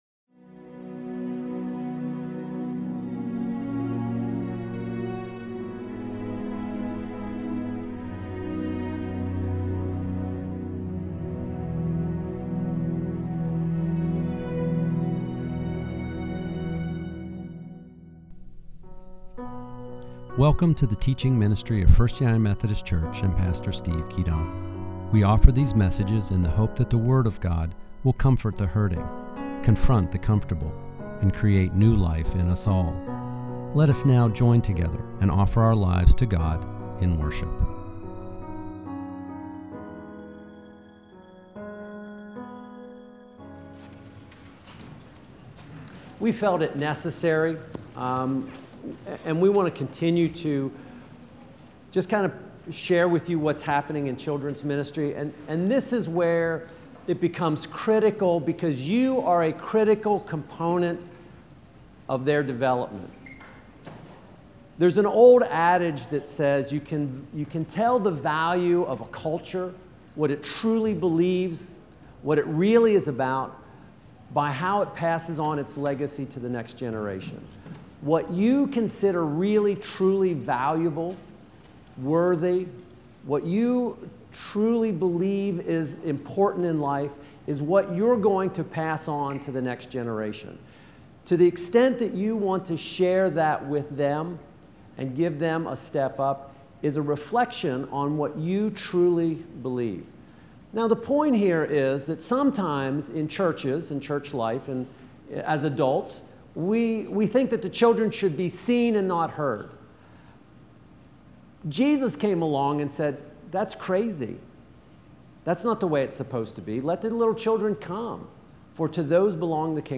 It was a cold Sunday morning when we gather for worship last Sunday.